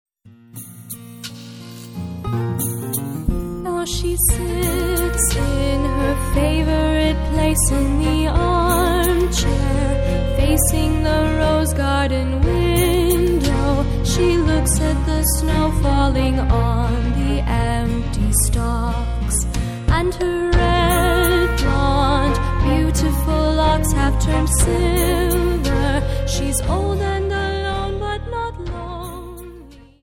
Dance: Viennese Wltz 59